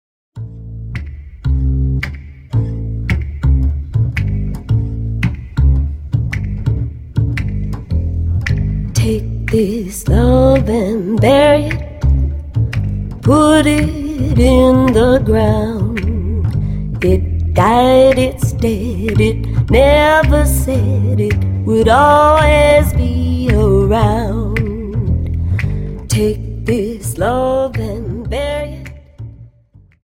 Dance: Slowfox 29 Song